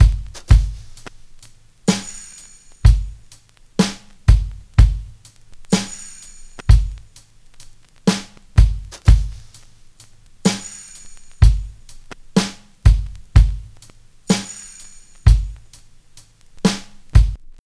56BRUSHBT1-R.wav